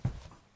krok_05.wav